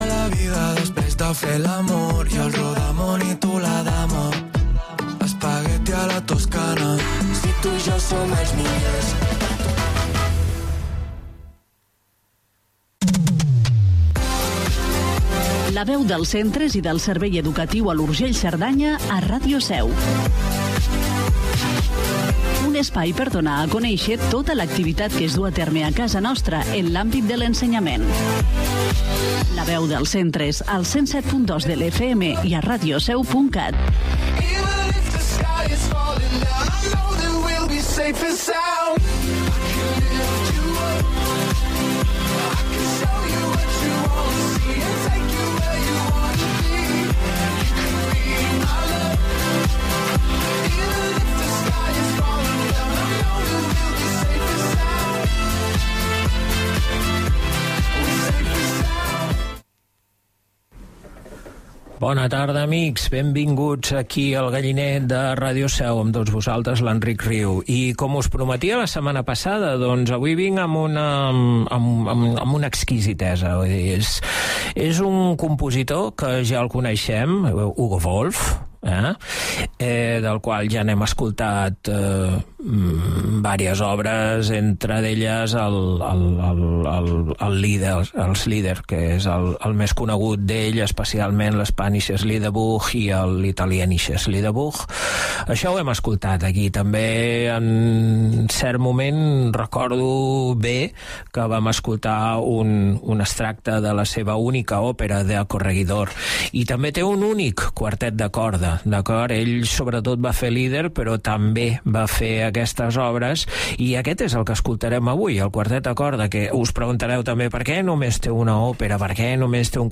Programa de música clàssica